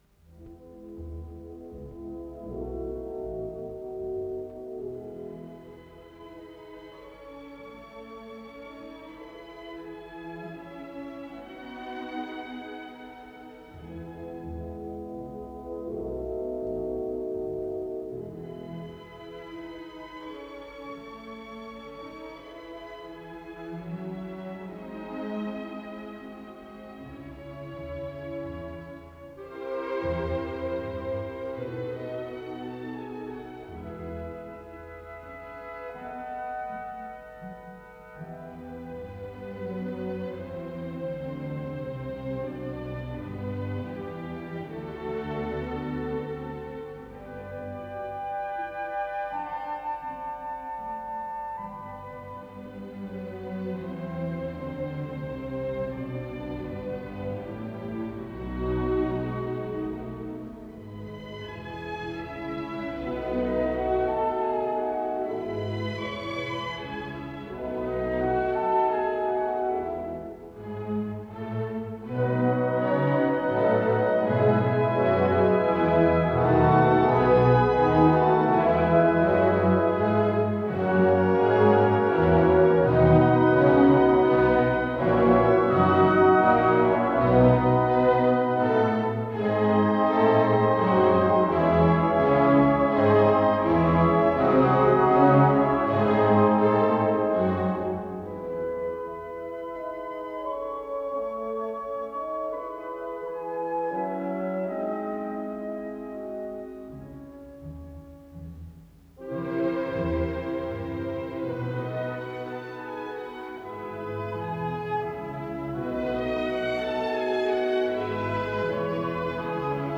Исполнитель: Академический симфонический оркестр Ленинградской государственной филармонии им. Дмитрия Шостакович
Симфония
Си минор